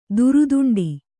♪ duruduṇḍi